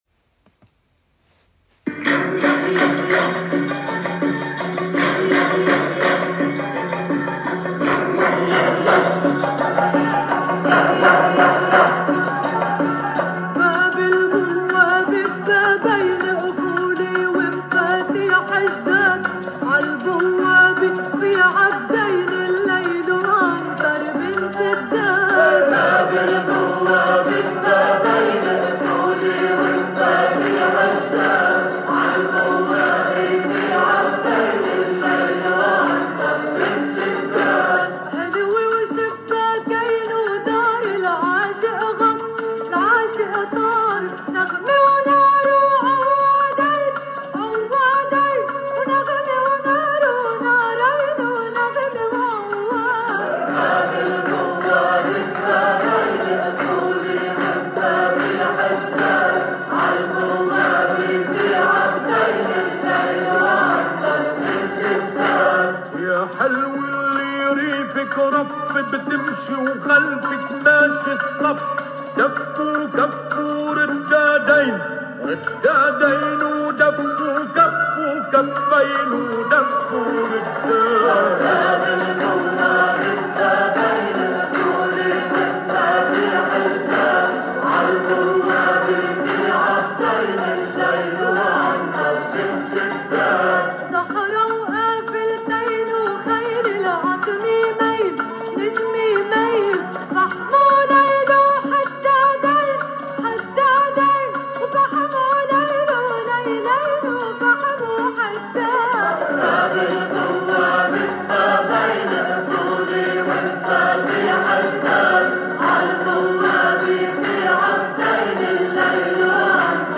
Baalbek